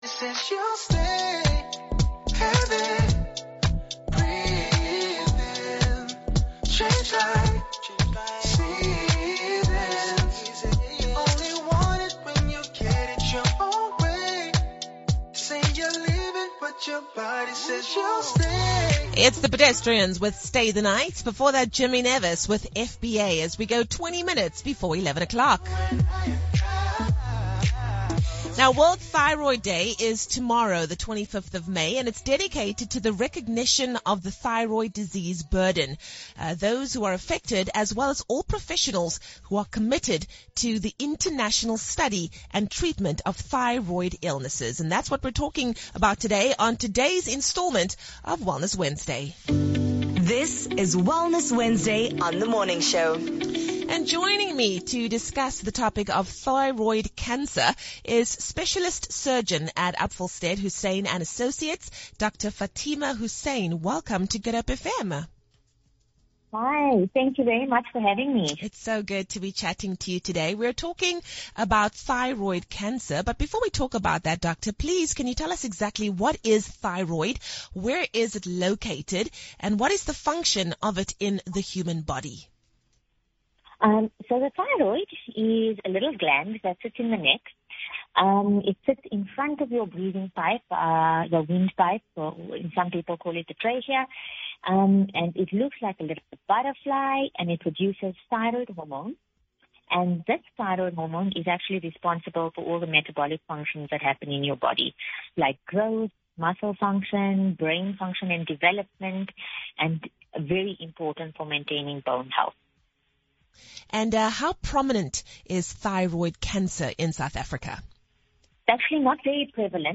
Good Hope FM interview for World Thyroid Day